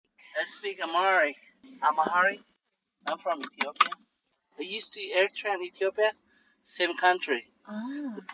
• Ethiopian male
• 30s